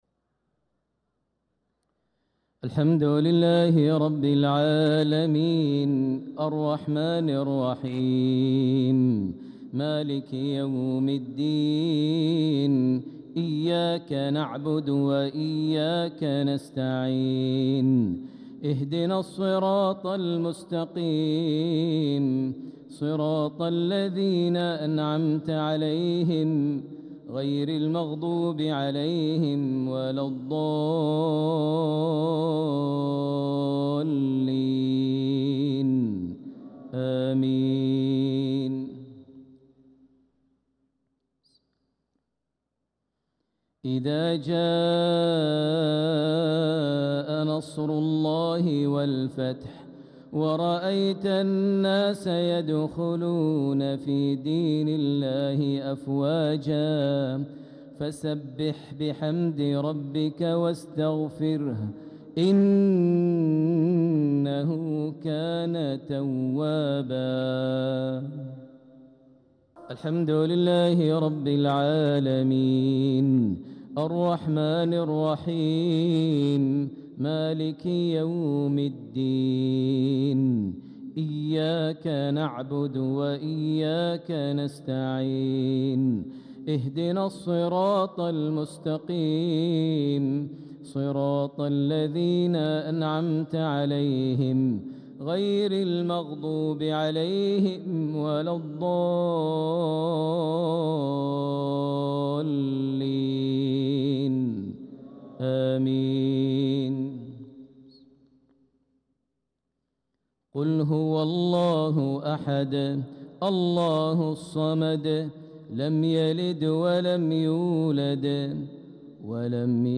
صلاة المغرب للقارئ ماهر المعيقلي 24 ذو الحجة 1445 هـ
تِلَاوَات الْحَرَمَيْن .